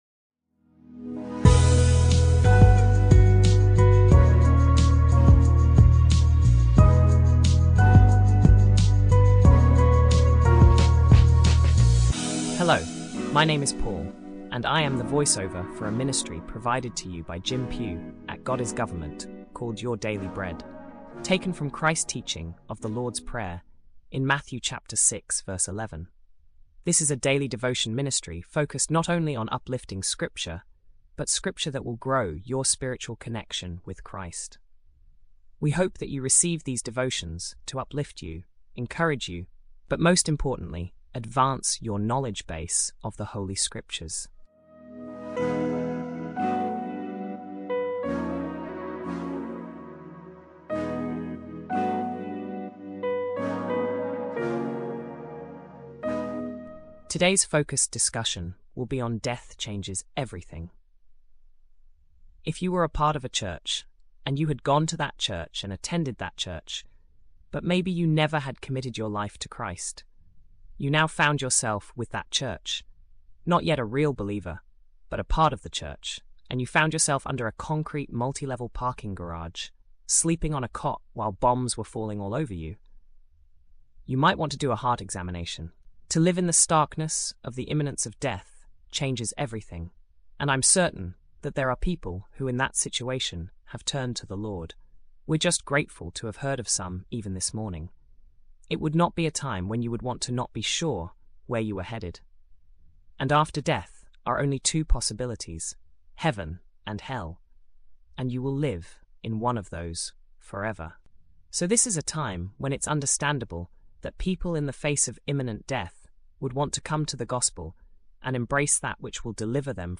shares daily devotions to help grow your spiritual connection with Christ.